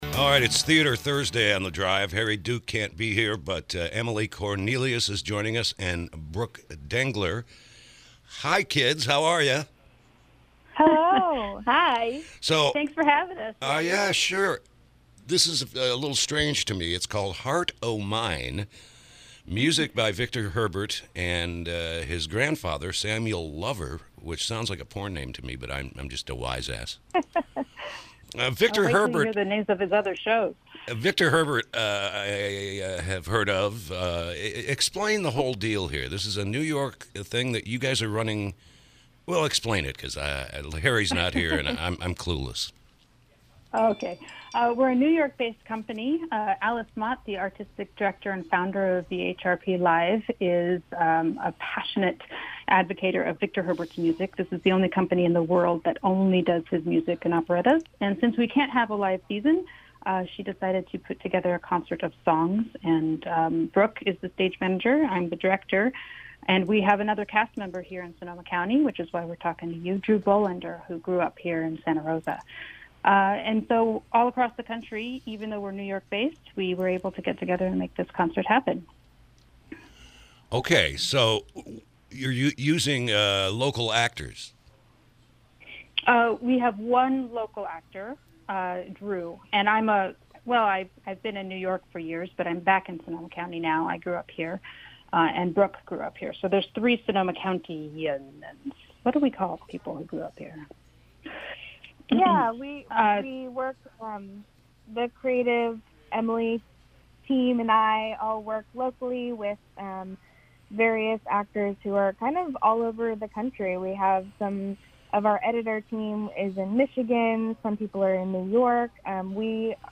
KSRO Interview – “Heart O’Mine”